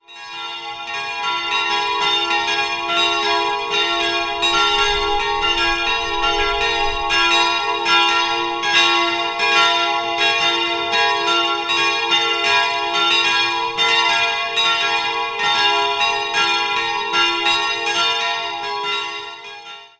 Jahrhundert ein Vorgängerbau. 3-stimmiges TeDeum-Geläute: f''-as''-b'' Die Glocken wurden 1971 in Heidelberg gegossen.